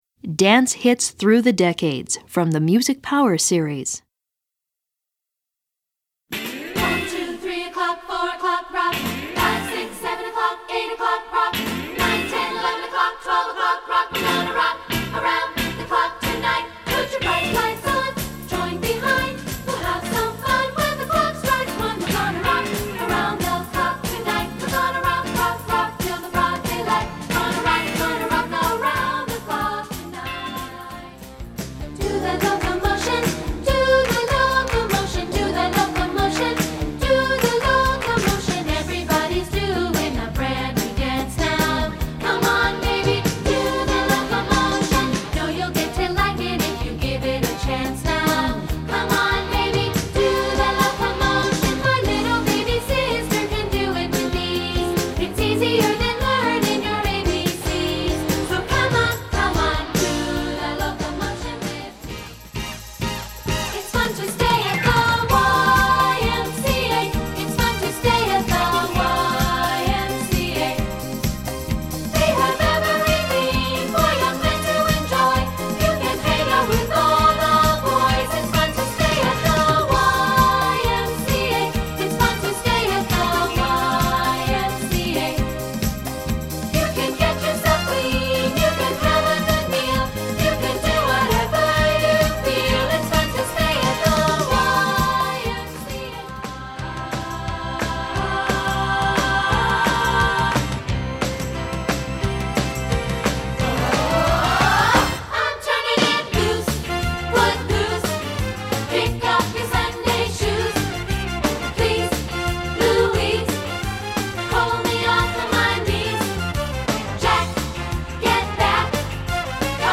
Choral Collections
Voicing